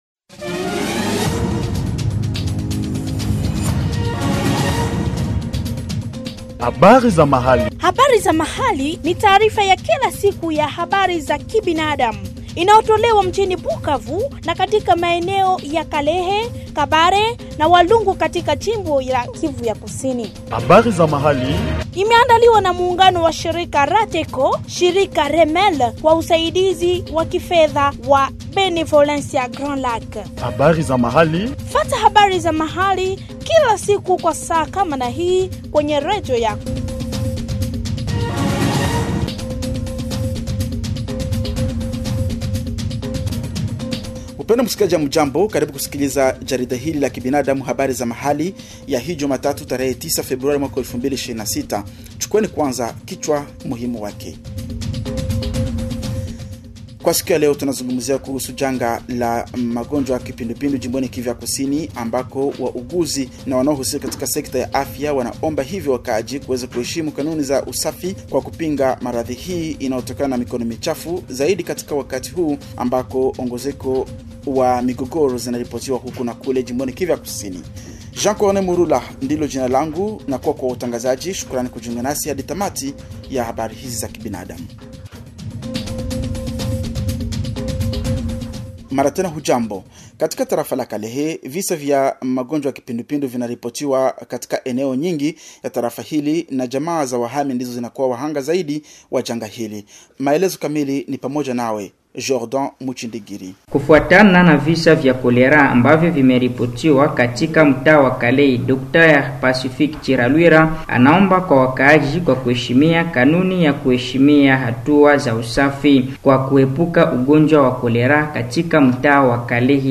Suivez ici le bulletin Habari za Mahali du 09 février 2026 produit au Sud-Kivu
BULLETIN-HZM-SWAHILI-DU-LUNDI-FEVRIER-2026.mp3